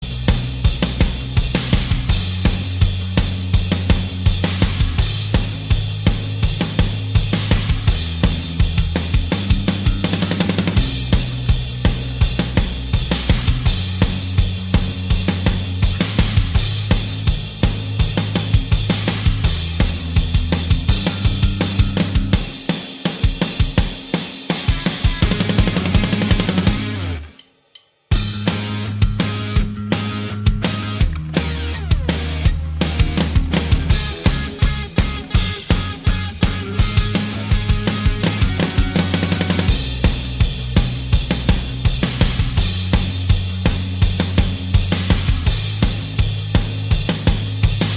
Minus Main Guitars Indie / Alternative 3:25 Buy £1.50